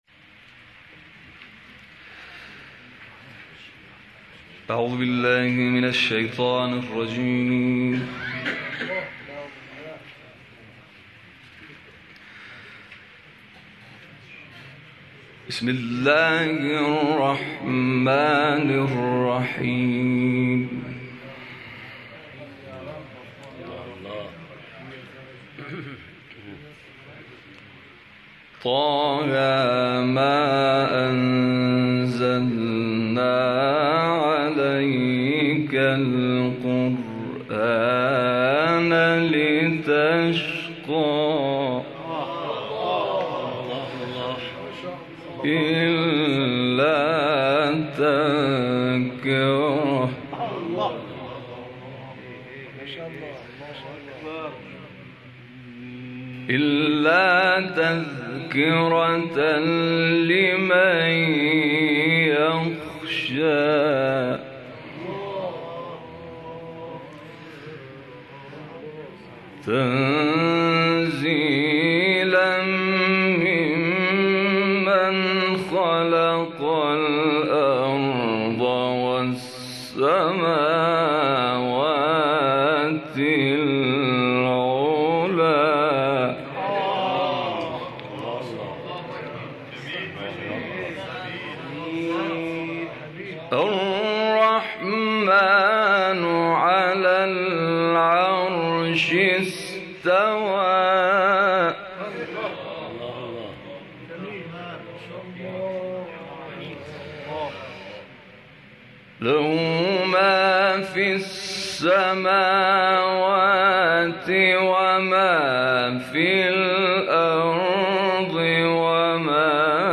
در جلسه‌ای که در شهر کرج با حضور پیشکسوتان قرآنی برگزار شد
تلاوت آیات 1 تا 23 سوره مبارکه طه